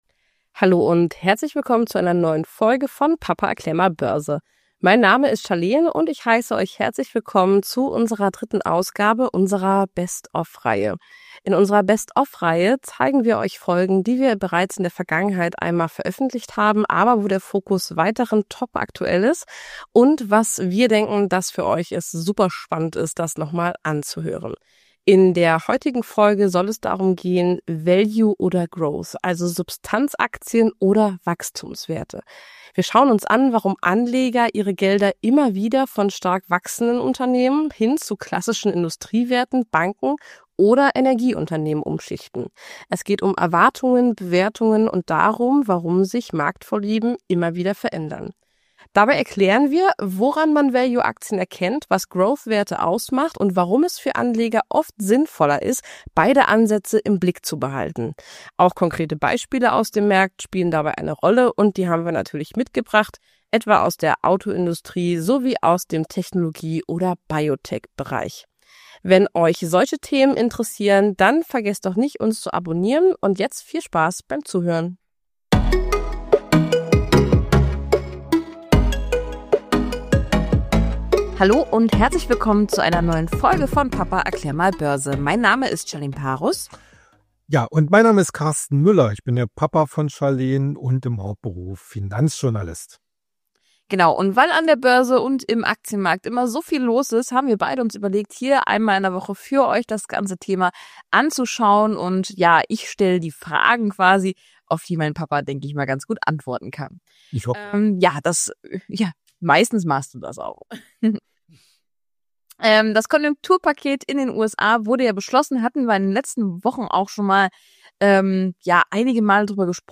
Im Gespräch zwischen Vater und Tochter geht es darum, warum sich Anlagestile an der Börse abwechseln, wie Anleger Marktphasen einordnen können und warum die Frage Value oder Growth auch heute noch hochaktuell ist.